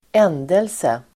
Uttal: [²'en:delse]